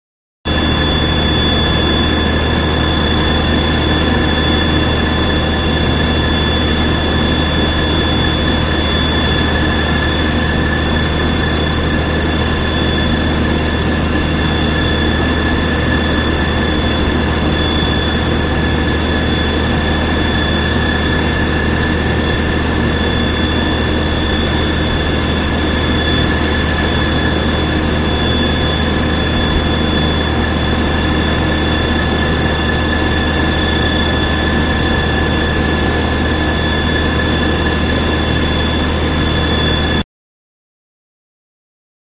TurbineContinuous PE274501
Turbine; Continuous Rumble With Steady-state High Pitched Whine, Close Perspective.